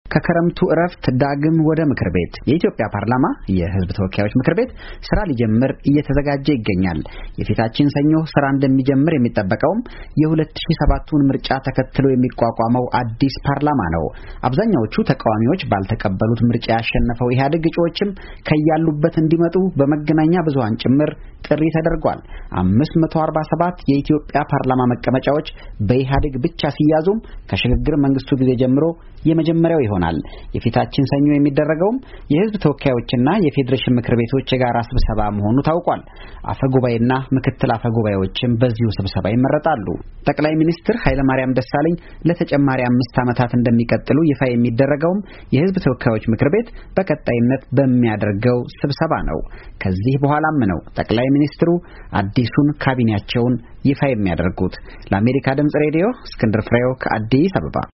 የድምጽ ዘገባ ከአዲስ አበባ